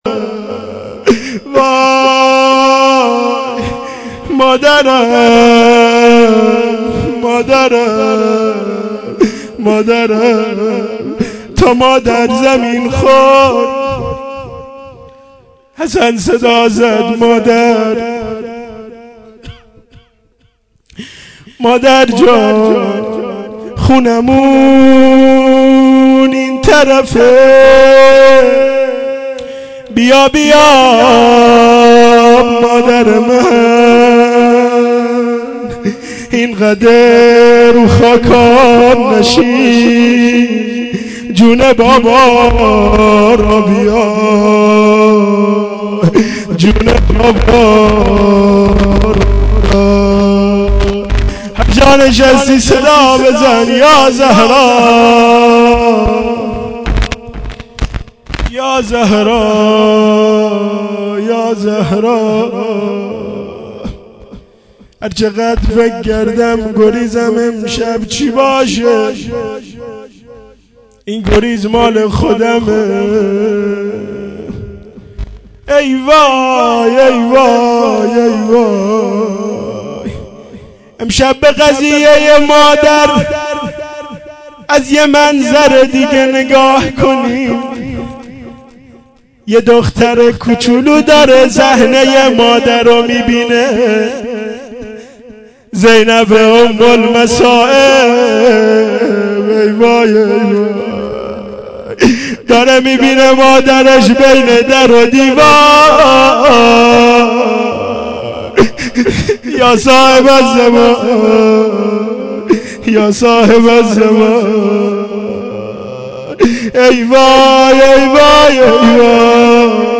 fatemieh-aval-92-shab2-rozeh-2.mp3